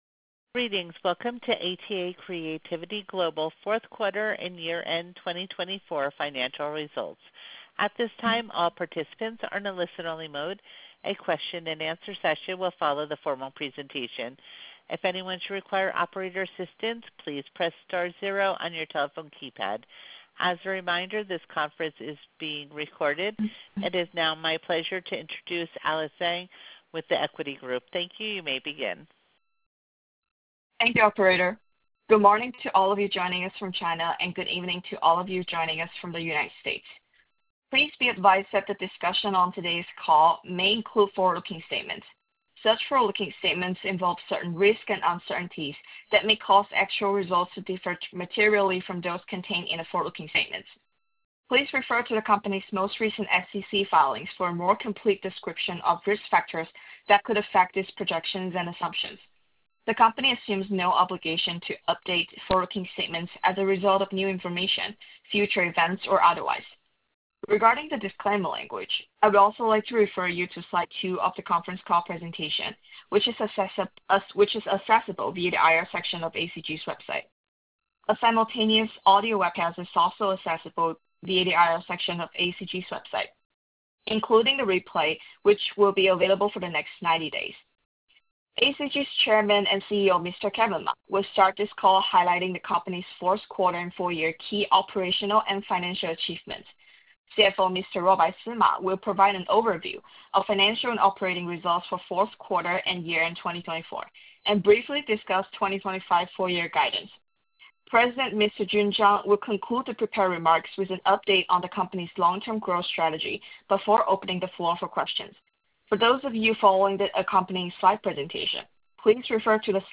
Q4 Fiscal Year 2024 Earnings Conference Call